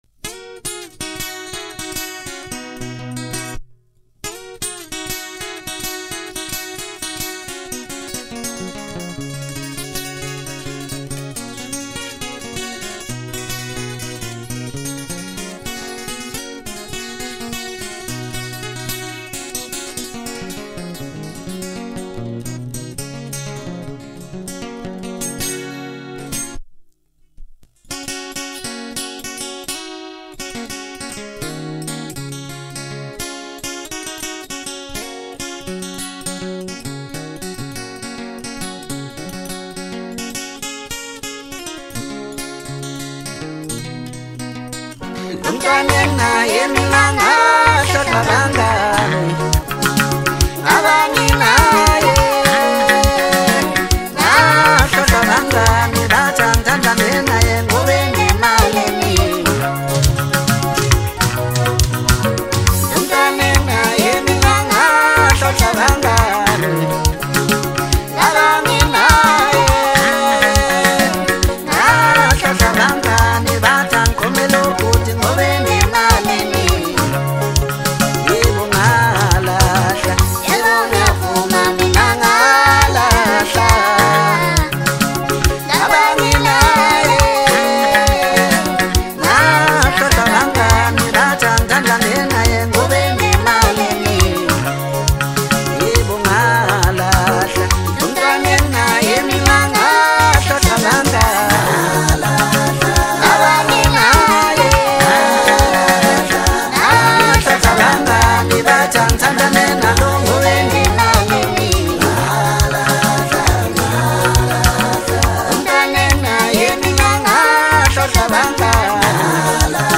Home » Maskandi » DJ Mix
South African Maskandi singer-songsmith